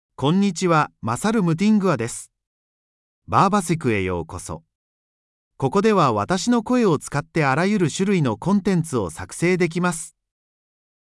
Masaru MultilingualMale Japanese AI voice
Masaru Multilingual is a male AI voice for Japanese (Japan).
Voice sample
Male
Masaru Multilingual delivers clear pronunciation with authentic Japan Japanese intonation, making your content sound professionally produced.